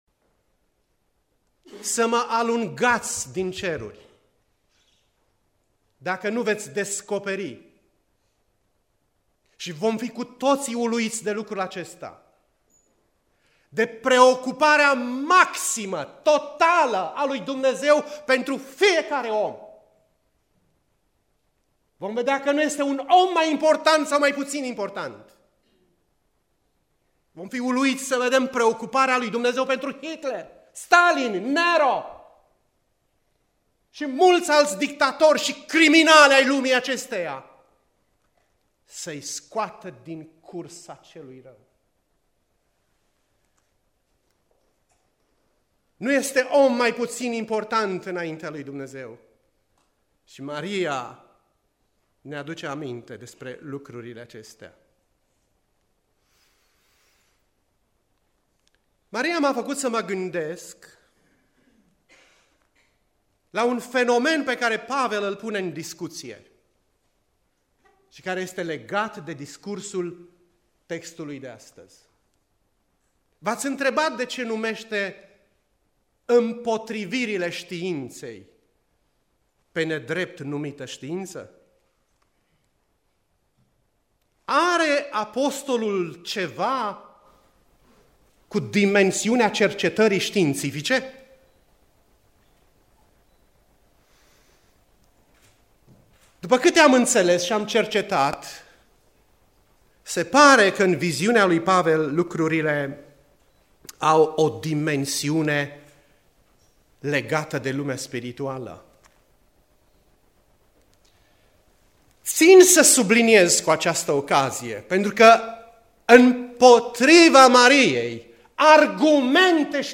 Predica Aplicatie 2 Timotei 2.14-2.26